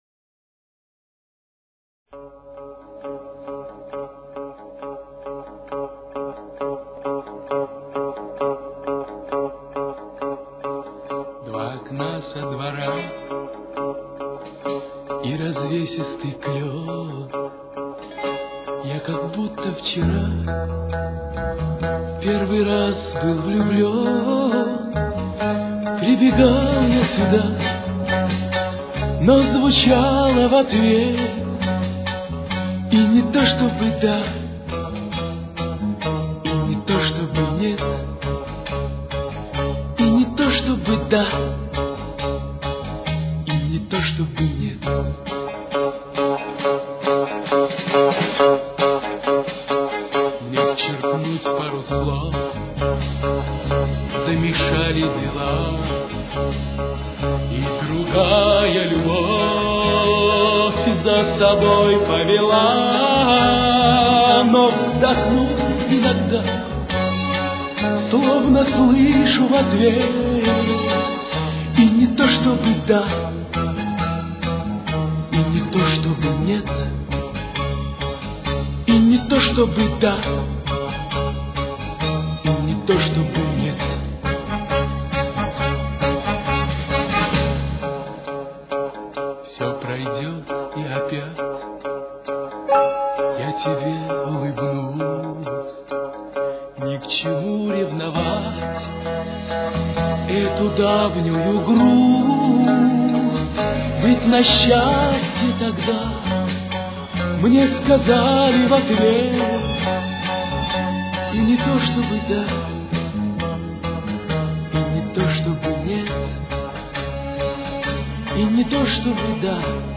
советский и российский эстрадный певец (баритон)